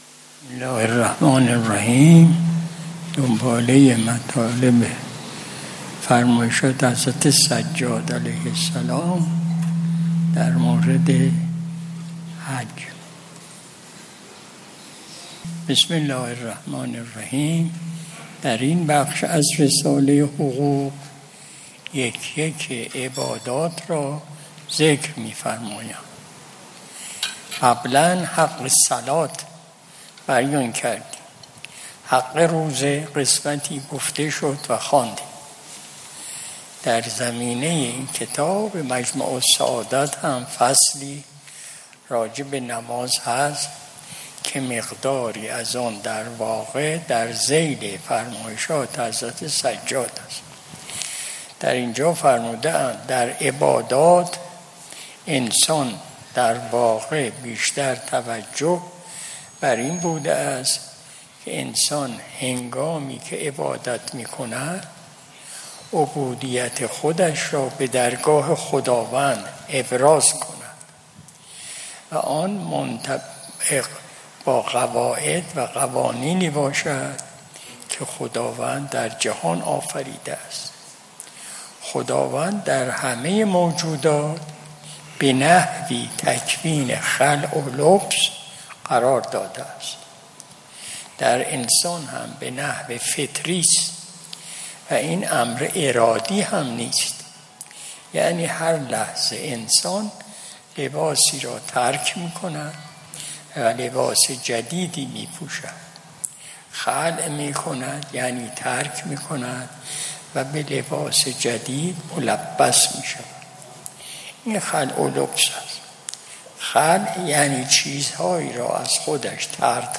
مجلس شب جمعه ۲۹ تیر ماه ۱۴۰۲ شمسی